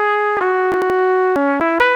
recorded audio breaking up
When I listen to the recording it sounds like it is breaking up.
Thanks. I can see the glitches.